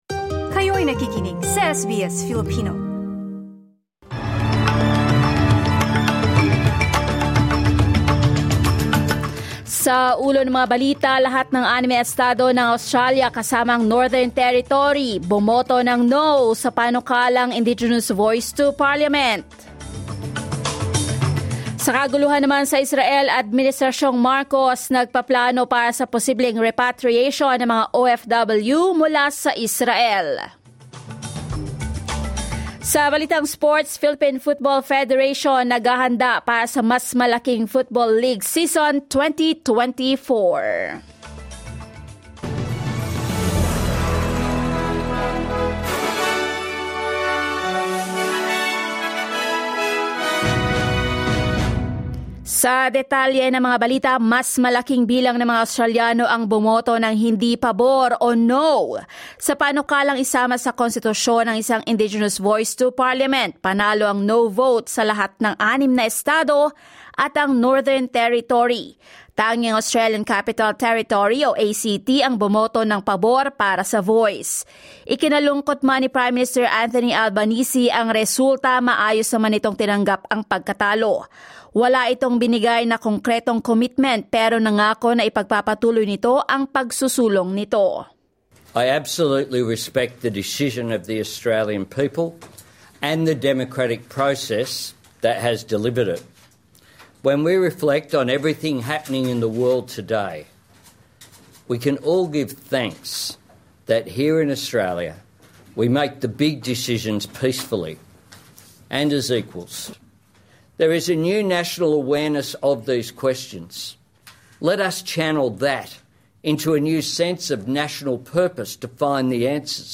SBS News in Filipino, Sunday 15 October 2023